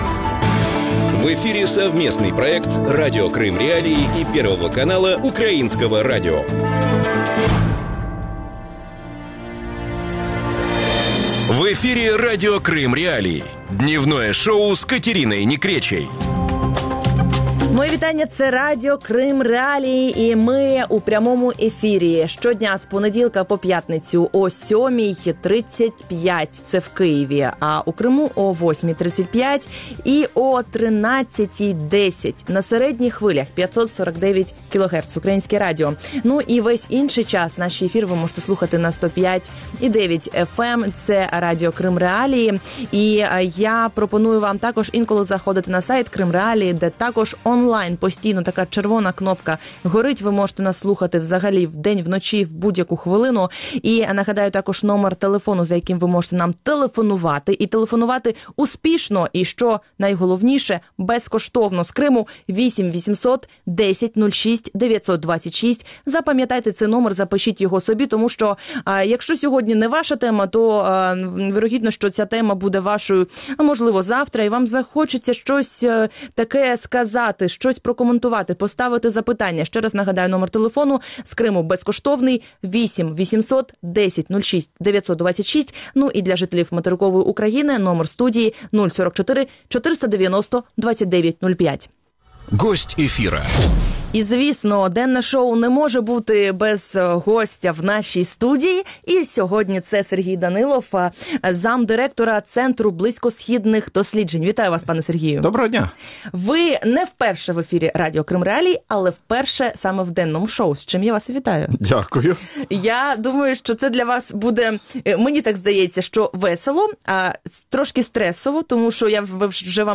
Украина, Крым и Турция. Интервью